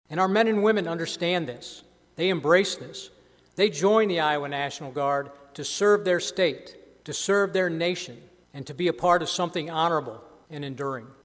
IOWA NATIONAL GUARD MAJOR GENERAL STEPHEN OSBORN USED THIS YEAR’S “CONDITION OF THE GUARD” SPEECH TO EXPLAIN WHY IOWA GUARDSMEN ARE DEPLOYED TO SYRIA AND HONOR THE TWO IOWA SOLDIERS KILLED THERE A MONTH AGO.